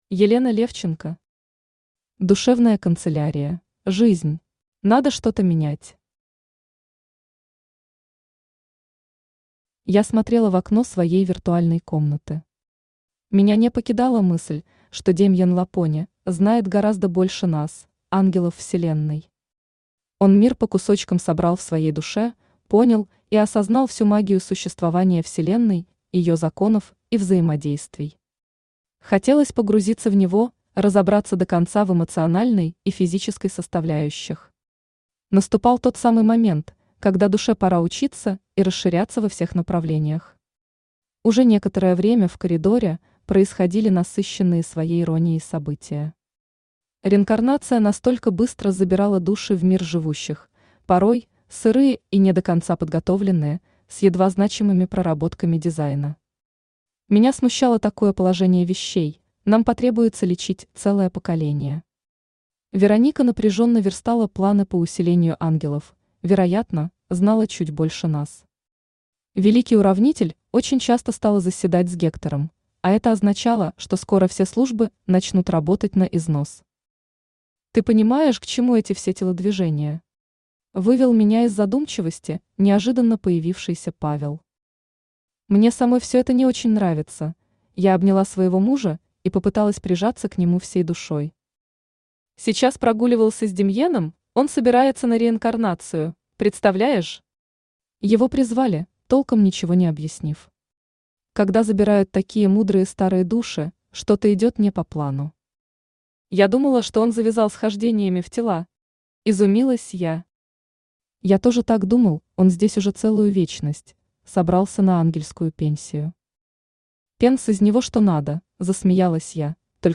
Аудиокнига Душевная канцелярия: Жизнь | Библиотека аудиокниг
Aудиокнига Душевная канцелярия: Жизнь Автор Елена Александровна Левченко Читает аудиокнигу Авточтец ЛитРес.